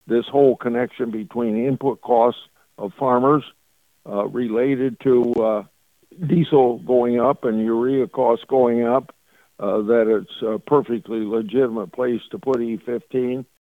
Senator Grassley made his comments on Tuesday during his weekly ag conference call with farm broadcasters and reporters.